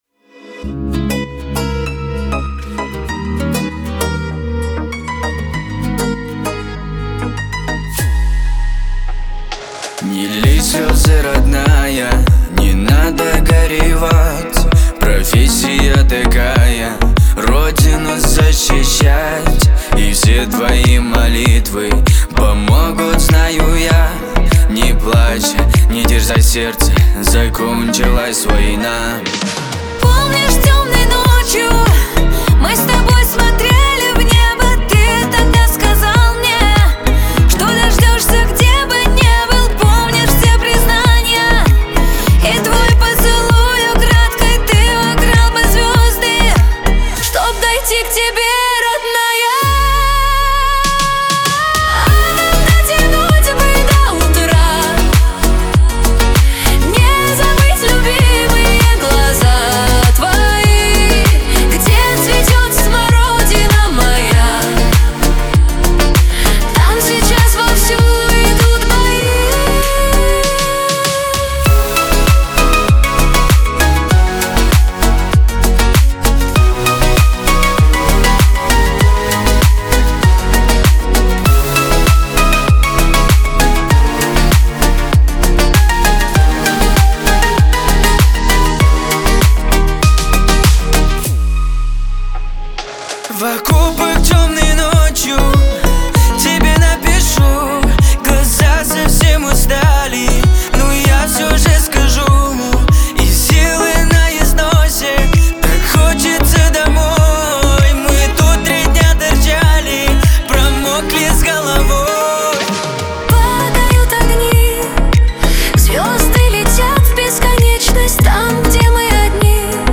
pop
эстрада
ХАУС-РЭП